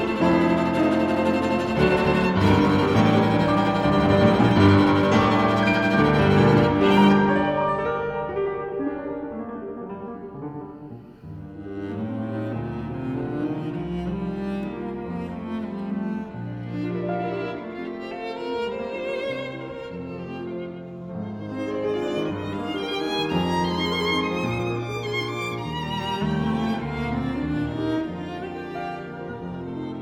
0 => "Musique de chambre"